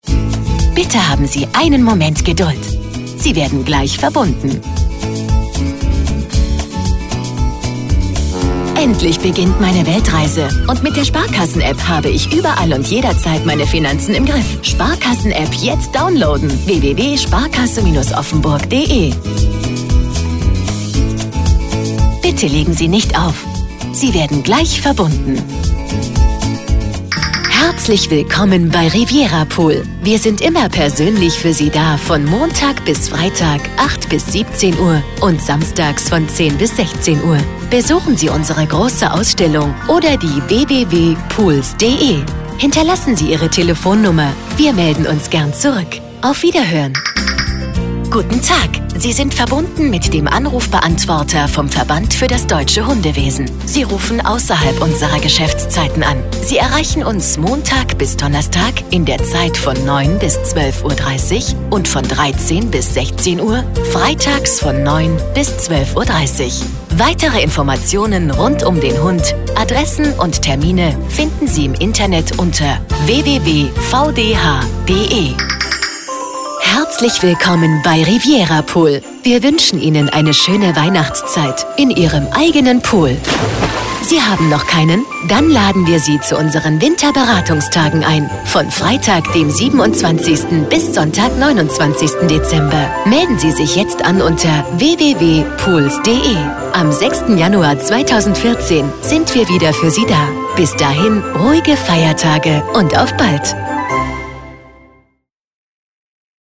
Anrufbeantworter-Ansage Sprecher - Synchronsprecher
🟢 Premium Sprecher